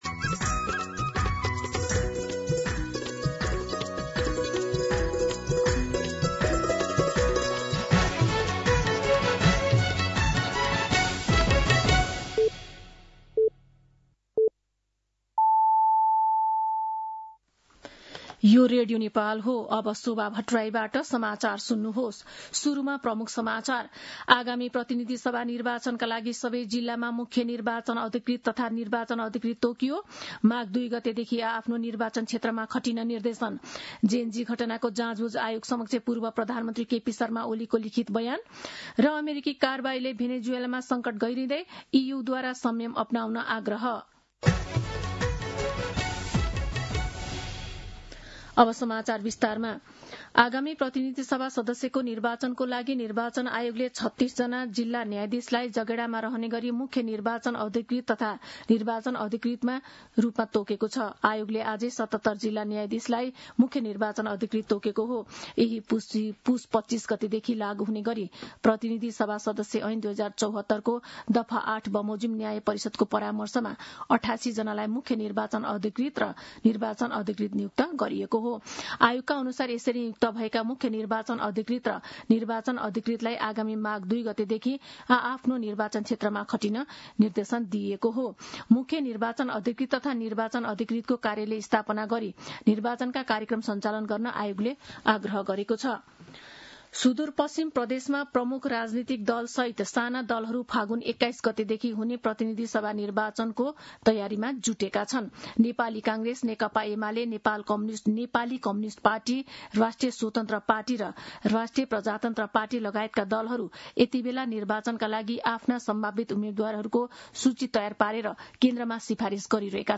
दिउँसो ३ बजेको नेपाली समाचार : २१ पुष , २०८२
3pm-News-21.mp3